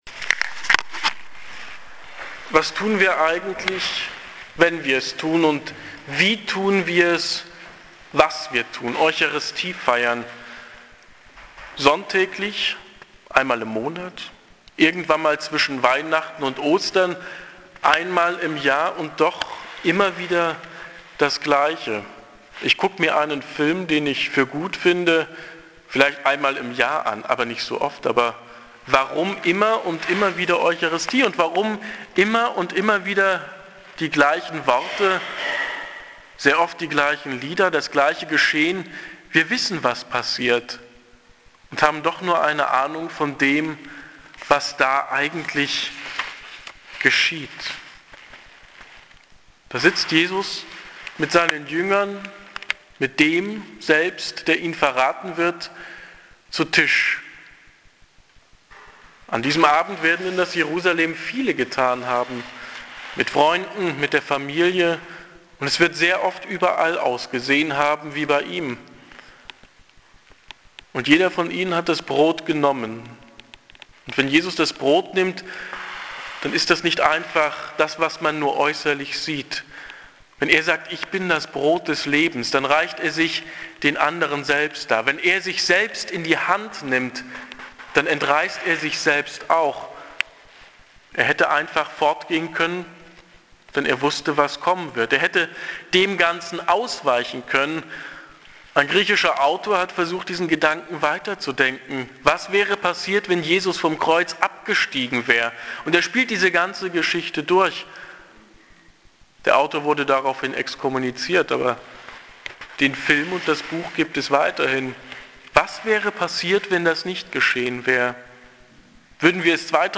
Predigt zum Gründonnerstag